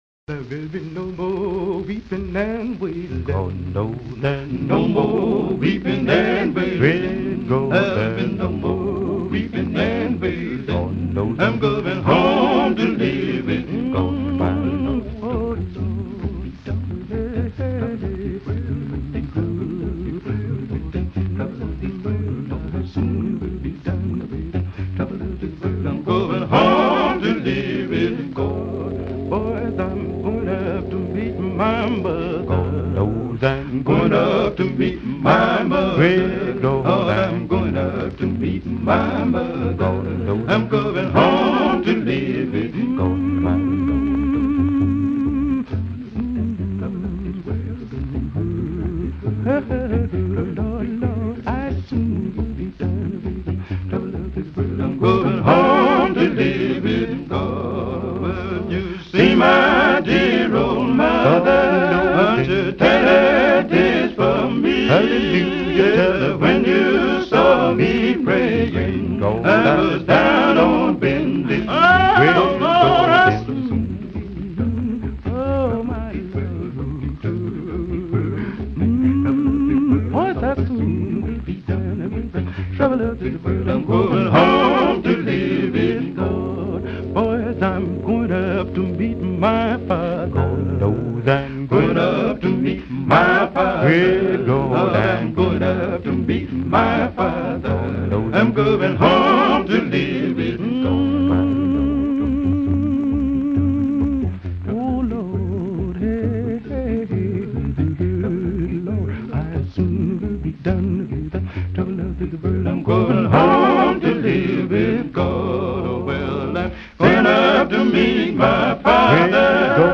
Pioneer Virginia gospel/pop quartet of the '30s and '40s.